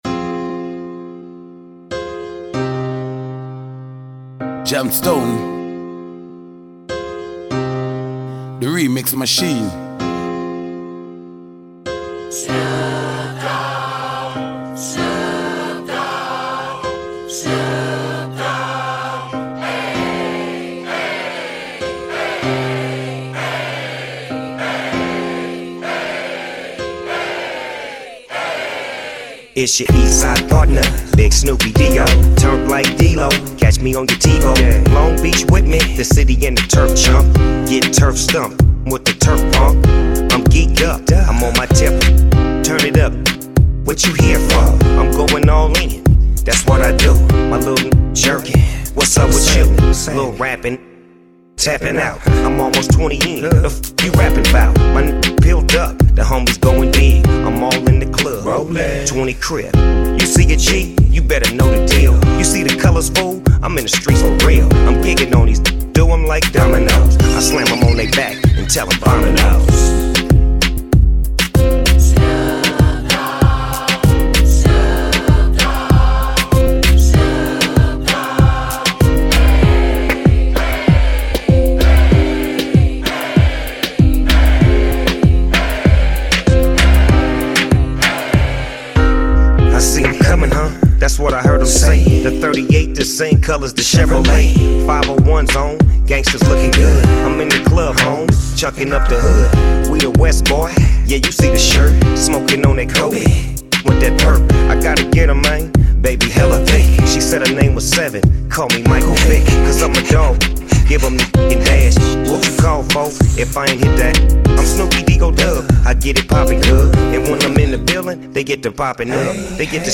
[REGGAETON RADIO EDIT]